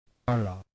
[ ɭ ]
U026D Retroflex non-fricative lateral.
U026D_long_l.mp3